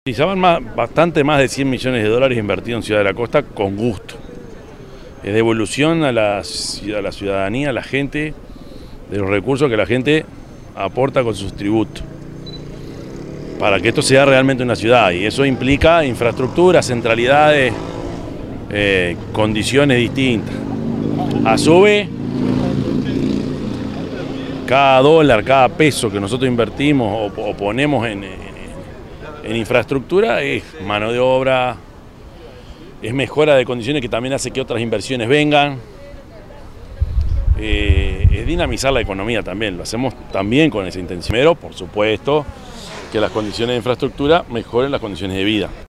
intendente_de_canelones_prof._yamandu_orsi_4.mp3